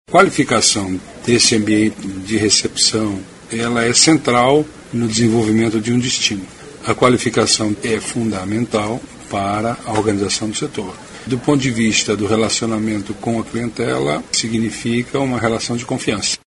aqui para ouvir declaração do secretário Vinicius Lummertz sobre a importância da formalização de negócios no setor turístico.